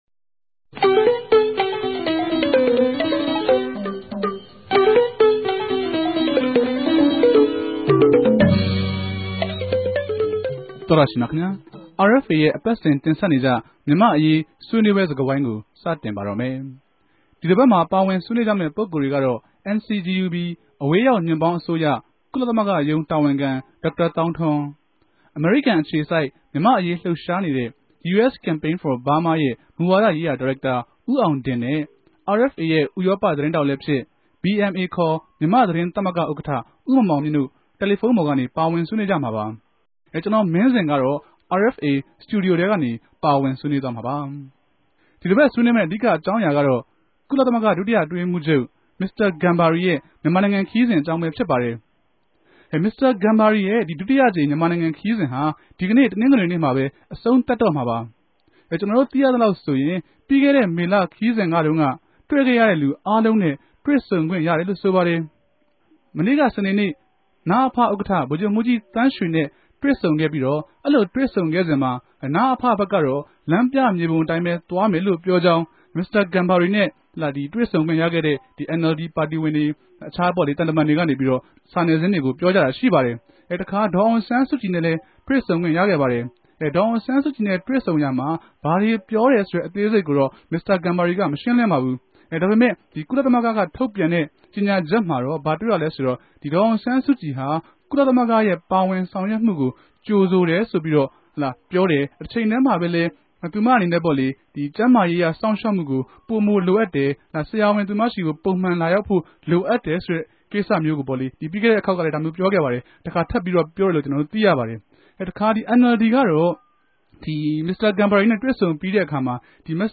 ဝၝရြင်တန်္ဘမိြႚ RFA စတူဒီယိုထဲကနေ